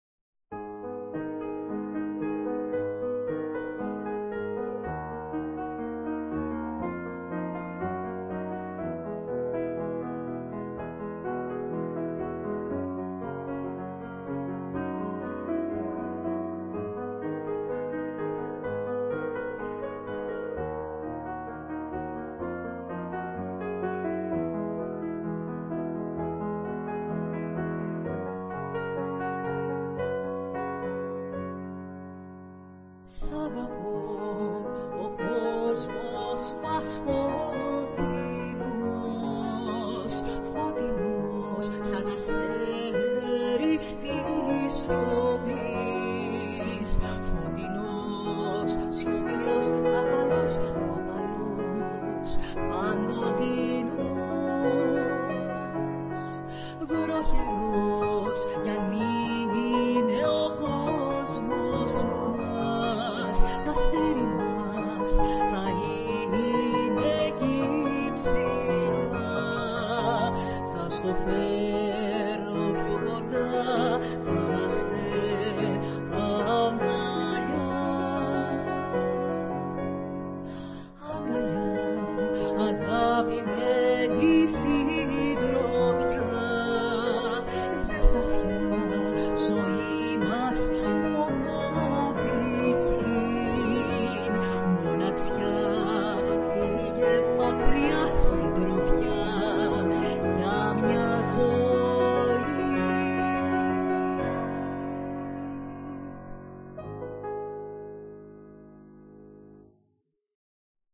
Η παρούσα έκδοση είναι rough version, χαμηλής ποιότητας, χρειάζεται μελέτη η συνοδεία του πιάνου για να γίνει καλύτερο και ένα καλύτερο μικρόφωνο να κόβει τα "pops".